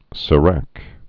(sə-răk, sā-)